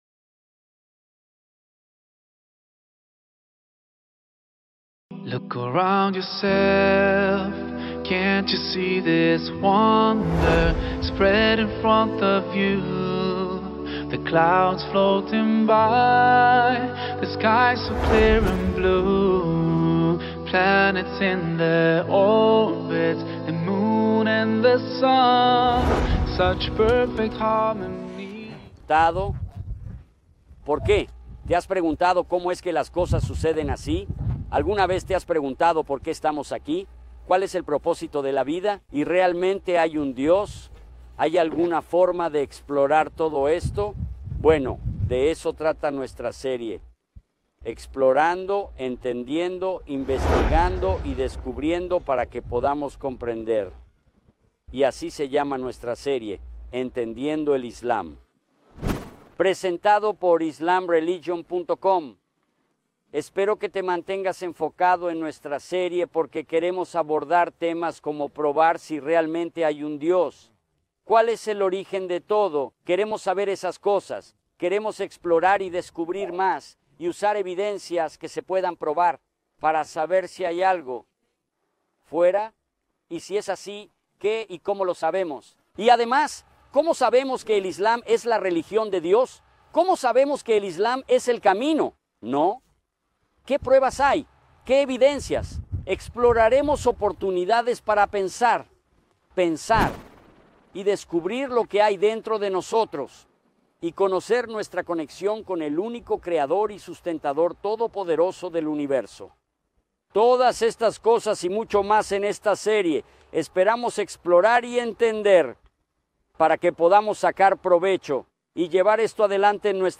filmada en paisajes escénicos y lugares históricos de Jordania. En este episodio, presenta una introducción a la serie.